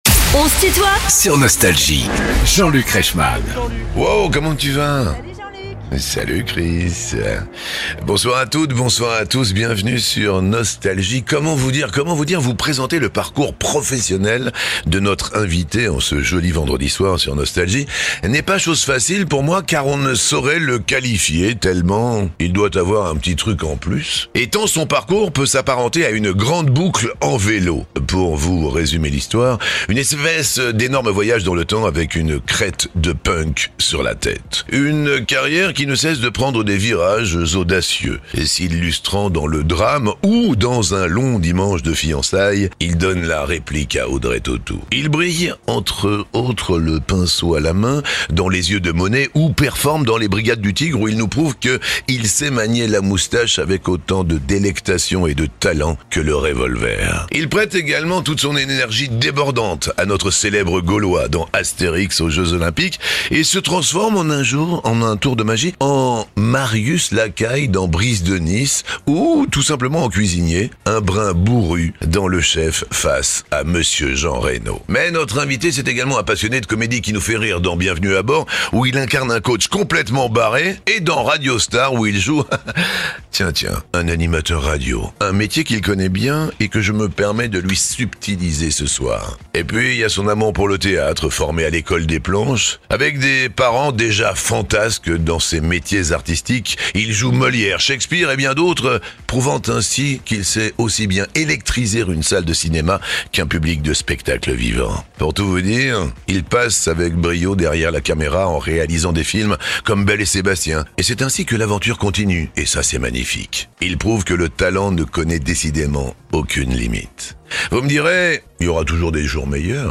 Jean-Luc Reichmann présente Clovis Cornillac dans son émission "On se tutoie ?..." sur Nostalgie
Les interviews
Clovis Cornillac est l'invité de "On se tutoie ?..." avec Jean-Luc